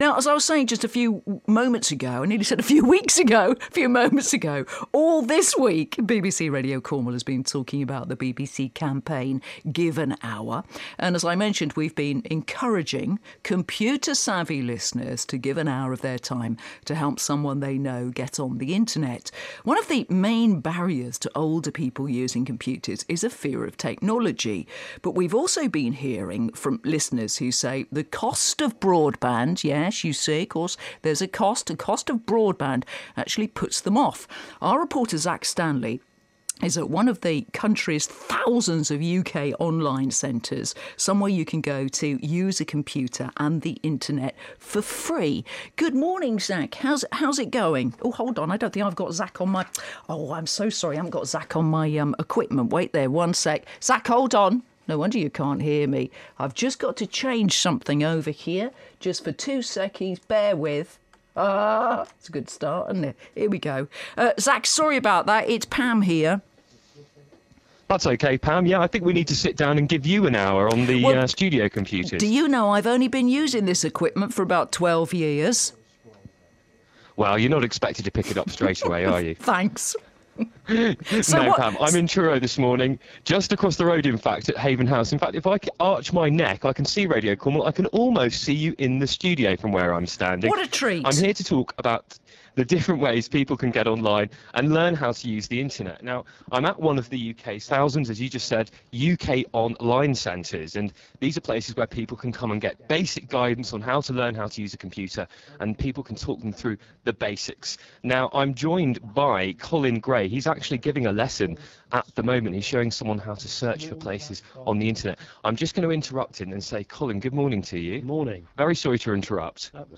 Give an Hour broadcast from Haven House